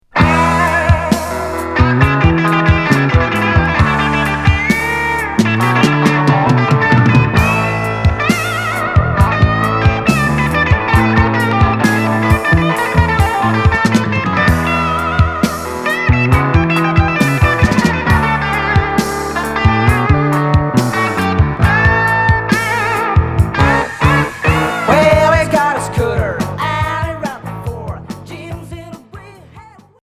Progressif Premier 45t retour à l'accueil